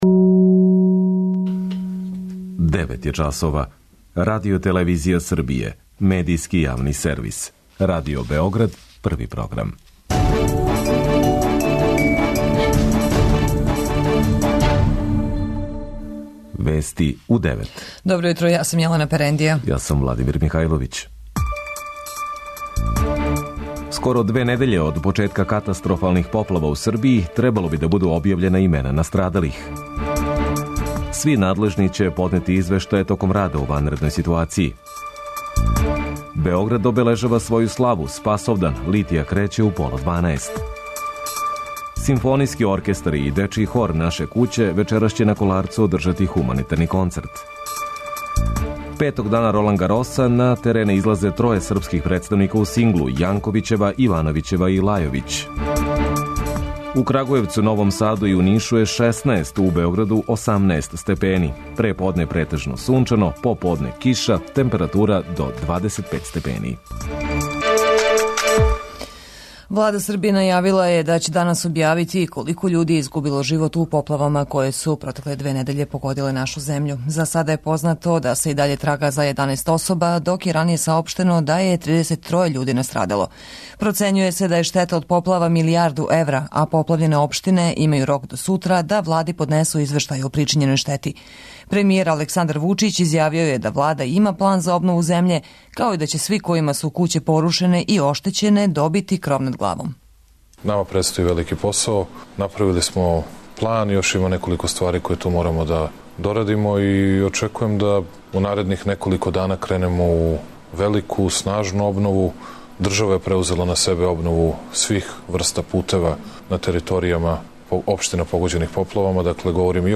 преузми : 10.09 MB Вести у 9 Autor: разни аутори Преглед најважнијиx информација из земље из света.